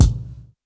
kits/RZA/Kicks/WTC_kYk (78).wav at main